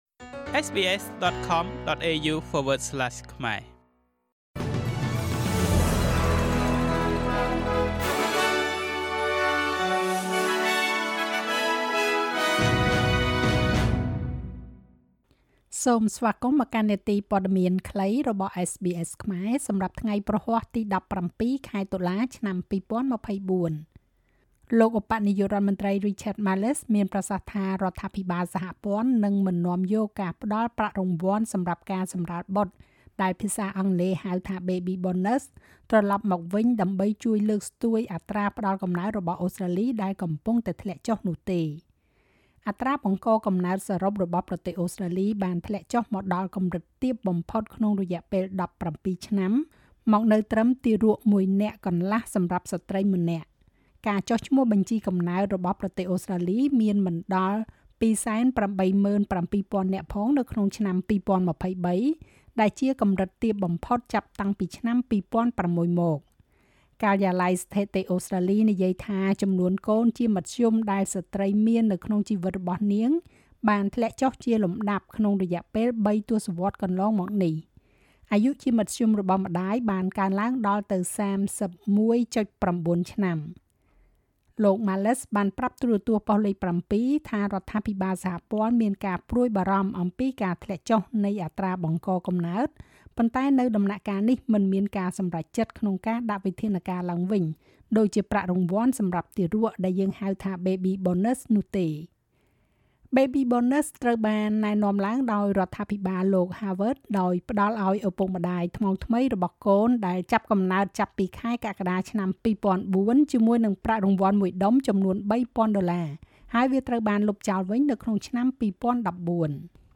នាទីព័ត៌មានខ្លីរបស់SBSខ្មែរ សម្រាប់ថ្ងៃព្រហស្បតិ៍ ទី១៧ ខែតុលា ឆ្នាំ២០២៤